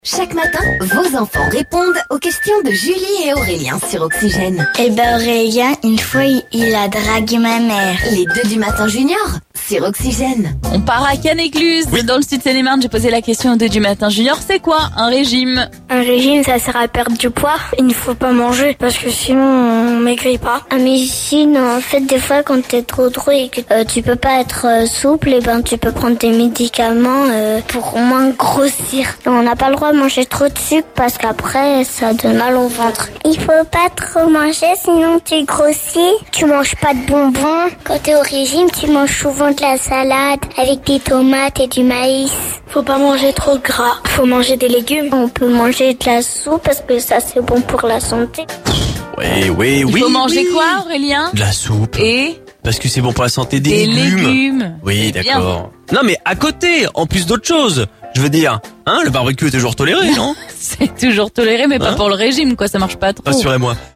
Écouter le podcast Télécharger le podcast Objectif summer body ? Écoutons les enfants Seine-et-Marnais nous expliquer ce qu'est un régime...